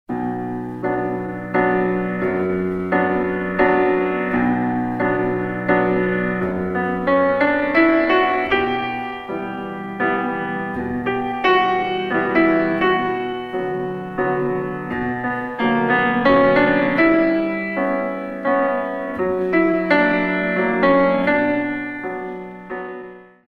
In 3